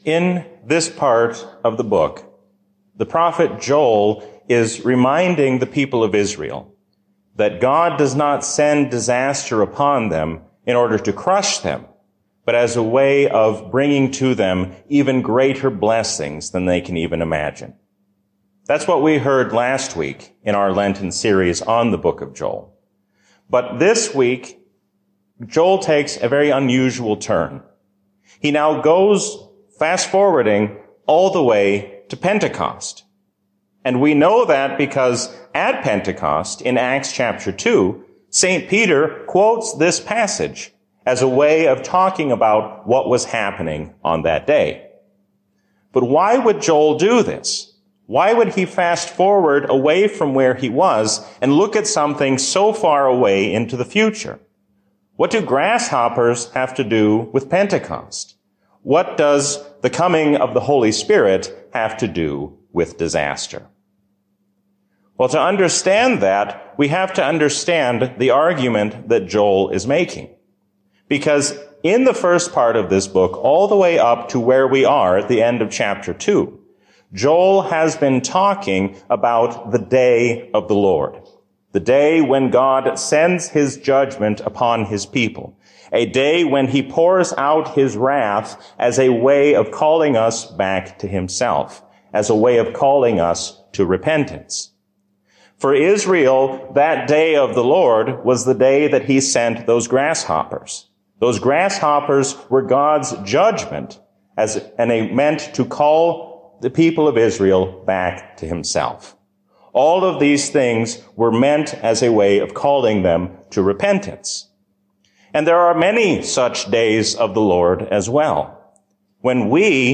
A sermon from the season "Lent 2020." Pursue Jesus in faith and never let go.